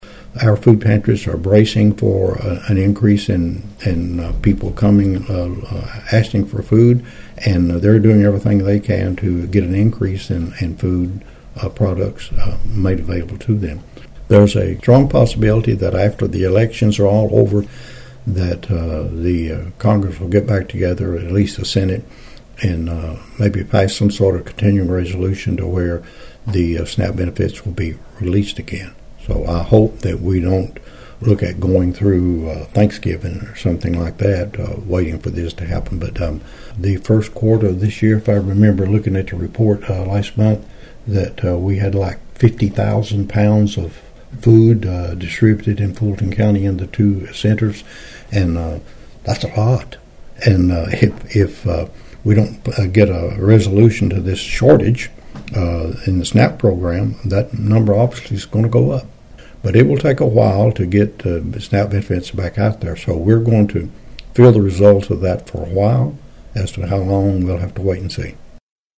Judge Martin said while there could be an agreement made soon on the shutdown, Fulton County food pantries will continue to seek additional resources to distribute.(AUDIO)